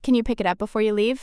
「ピック・イット・アップ」ではなくて、
「ピキトアップ」と音が連結したように聞こえたんじゃないでしょうか？